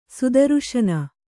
♪ sudaruśana